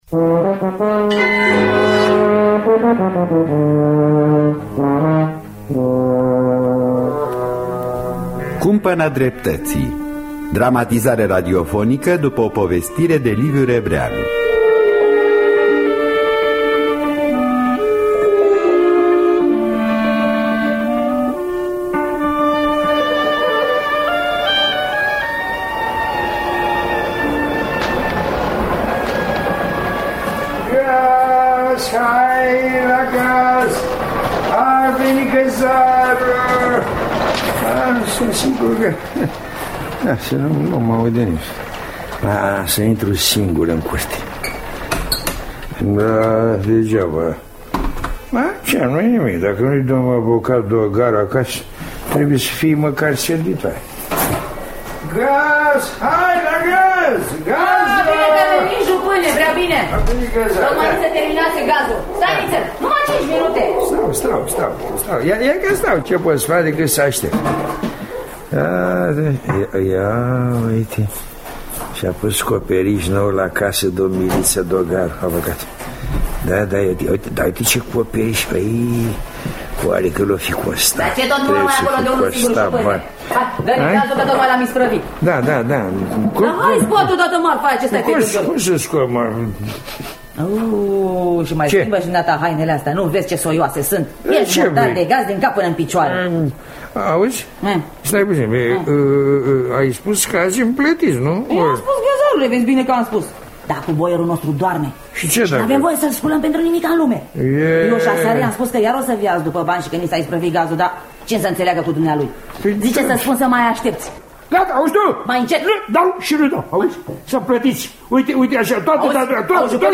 Dramatizarea radiofonicã
Înregistrare din anul 1989 https